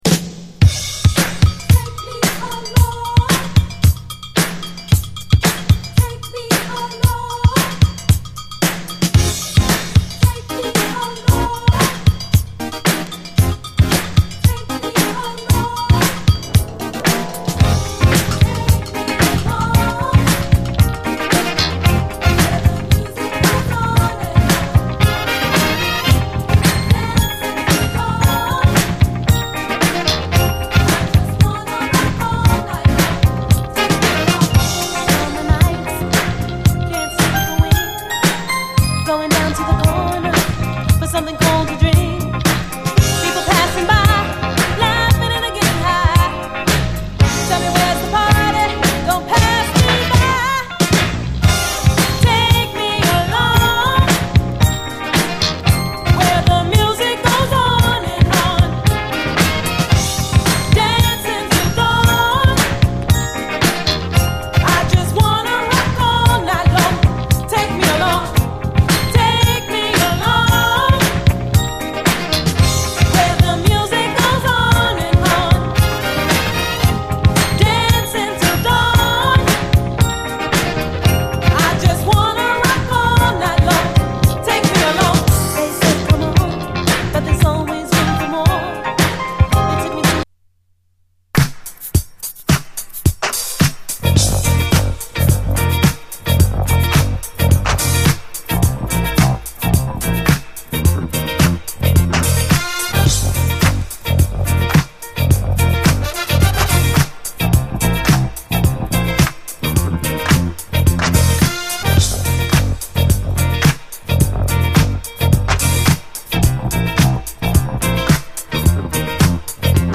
SOUL, 70's～ SOUL, DISCO
メロウ・シンセ・ブギー
ガラージ・クラシック
鮮やかなシンセ・フレーズが切り裂くメロウ・シンセ・ブギー